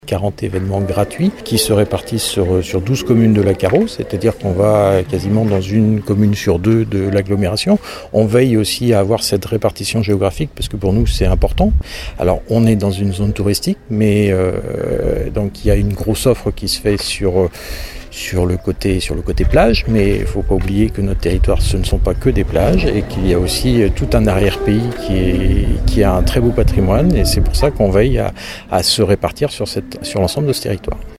Autant d’évènements qui vont permettre de faire le tour du territoire cet été, comme le souligne Claude Maugan, vice-président de la CARO en charge de la politique culturelle :